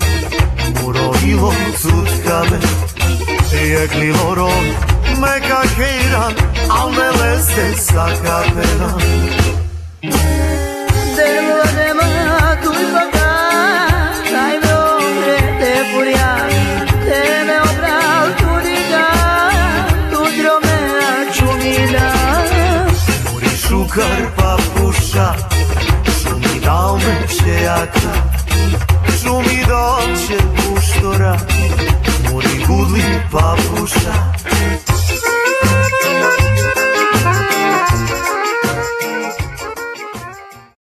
gitara guitar
akordeon accordion
skrzypce violin
kontrabas double bass
instr. perkusyjne percussions